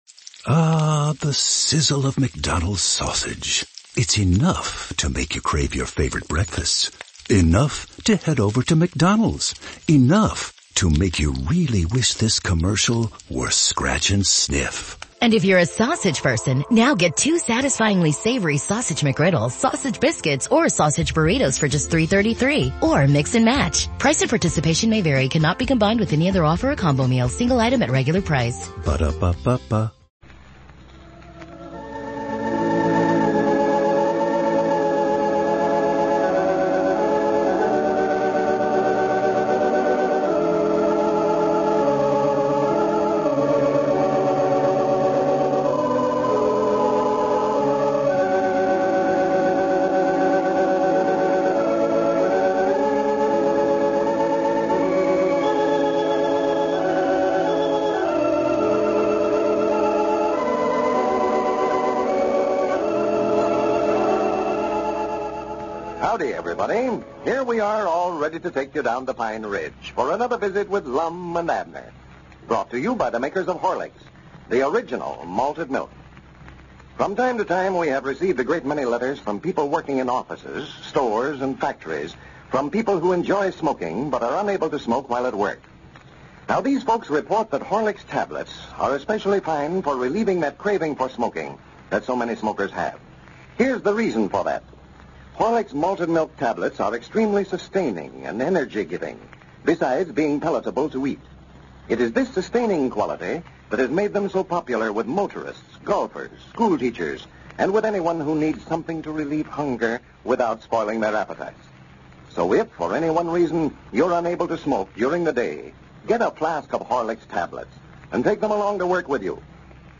A classic radio show that brought laughter to millions of Americans from 1931 to 1954.